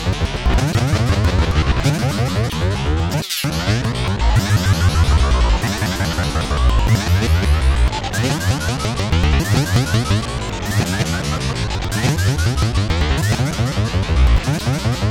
game bouncy fracture textured fx.ogg
Original creative-commons licensed sounds for DJ's and music producers, recorded with high quality studio microphones.